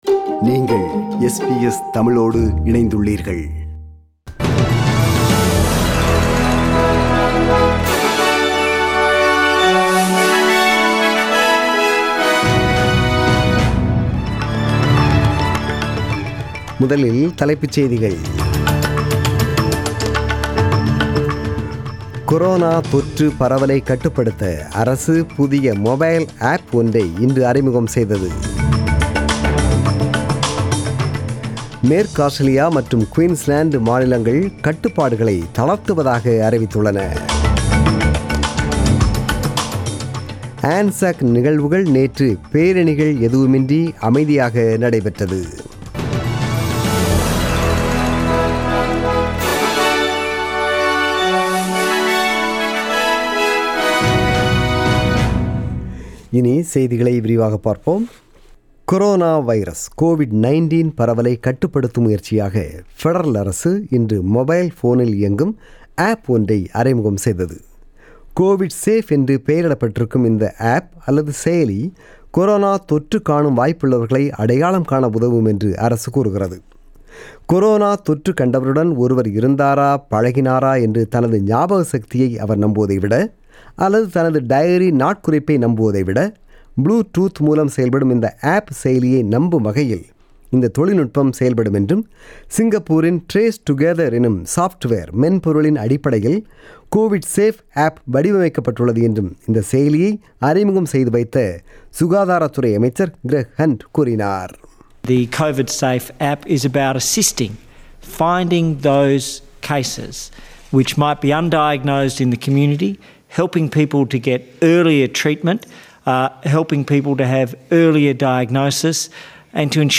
The news bulletin was broadcasted on 26 April 2020 (Sunday) at 8pm.